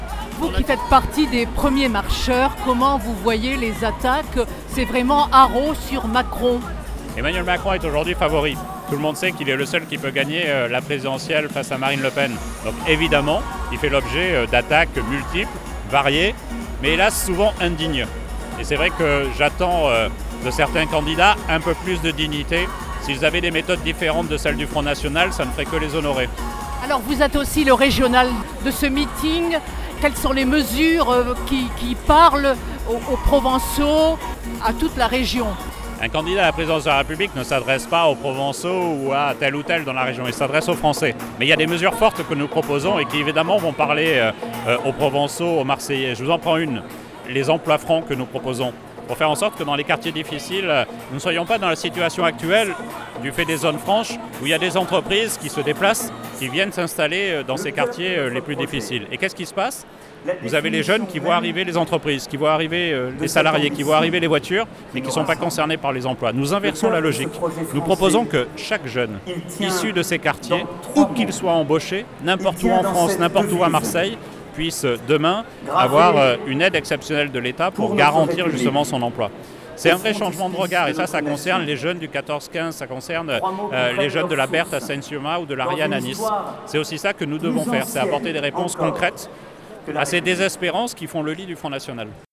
ste-228_christophe_castaner_meeting_macron.mp3